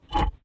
sfx_push_boulder.wav